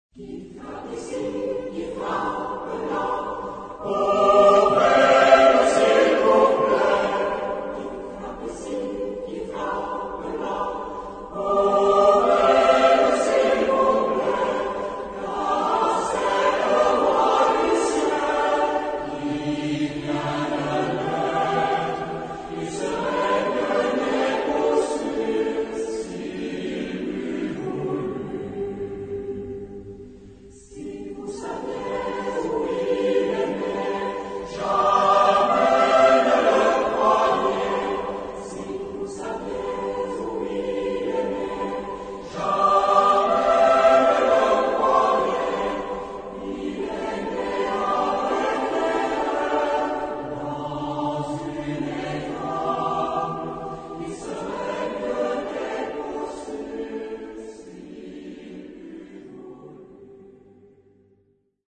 Genre-Style-Form: Popular ; Secular ; Christmas song
Type of Choir: SATB  (4 mixed voices )
Tonality: G major
Origin: Quercy (F)